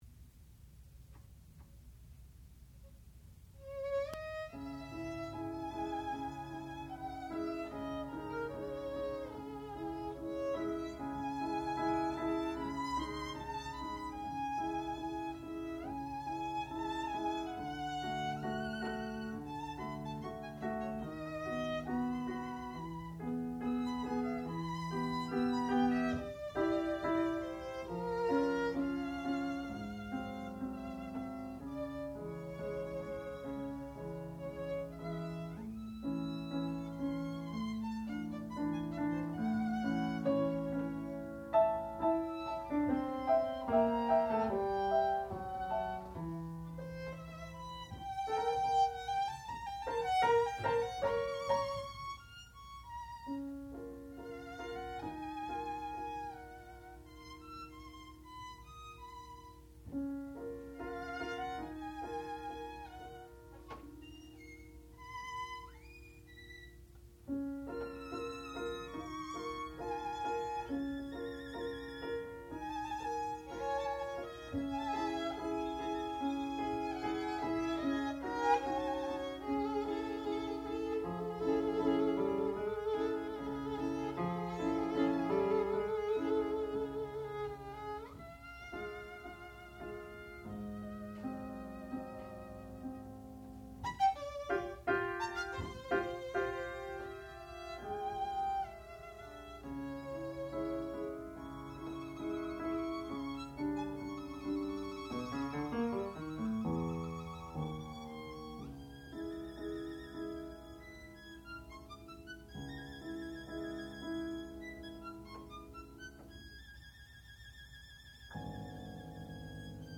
classical music
violin
piano
Master's Recital